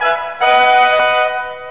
sndTokenJackpot.wav